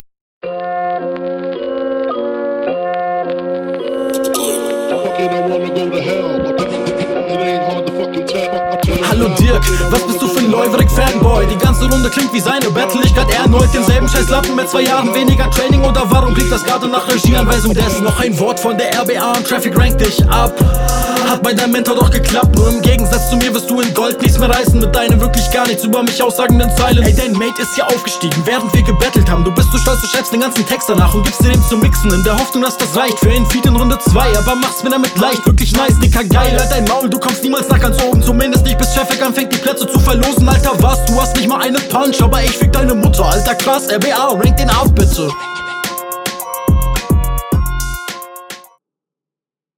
Raptechnisch nicht ganz so cool …